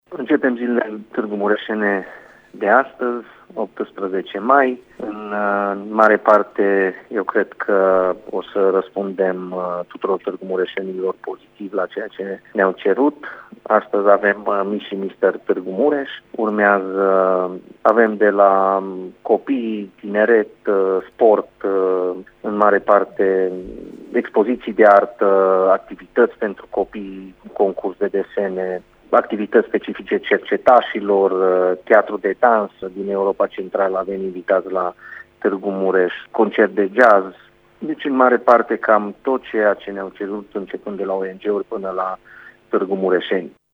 Timp de o săptămână, Primăria Tîrgu-Mureş a pregătit programe sportive, culturale, educaţionale şi de divertisment pentru a răspunde solicitărilor populaţiei şi societăţii civile, spune viceprimarul Claudiu Maior: